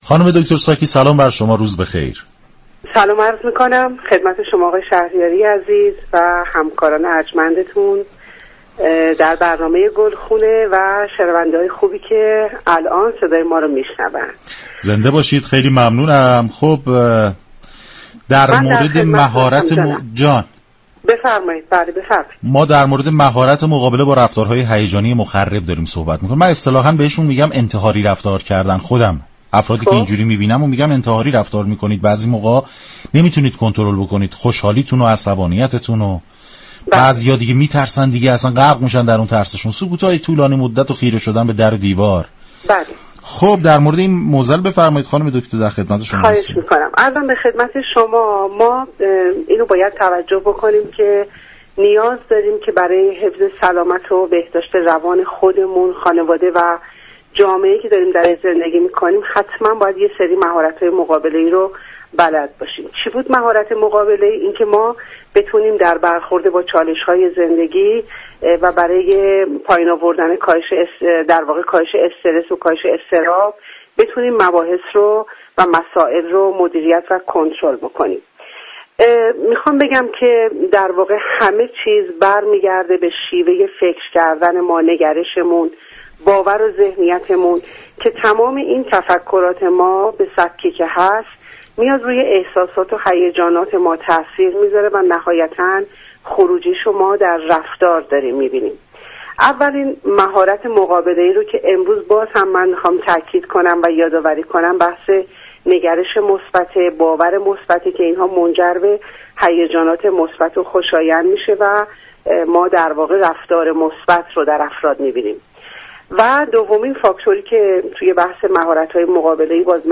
این برنامه با محوریت ترویج سبك زندگی اسلامی ایرانی با هدف ایجاد و تقویت انگیزه برای بهبود وضعیت سواد حركتی در نهاد خانواده ساعت 10 هر روز به مدت 60 دقیقه از شبكه رادیویی ورزش تقدیم شنوندگان می شود.